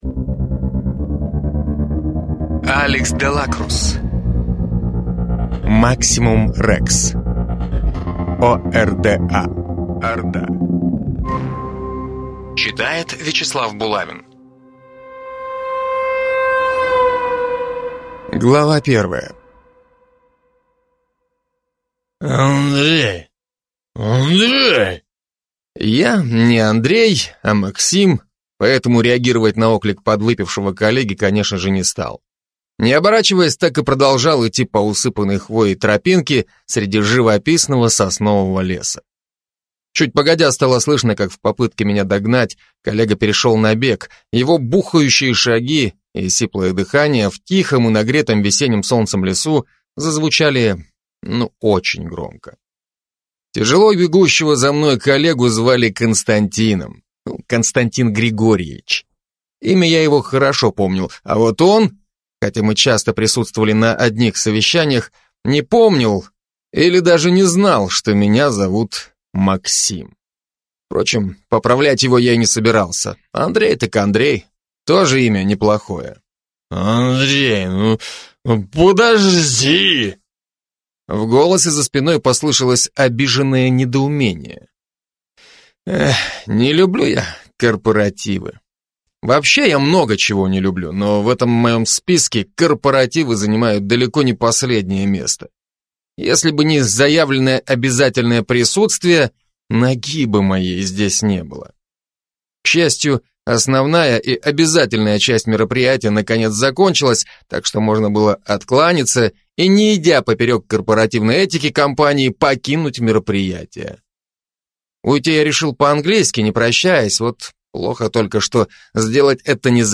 Аудиокнига Maximus Rex: О.Р.Д.А. | Библиотека аудиокниг
Прослушать и бесплатно скачать фрагмент аудиокниги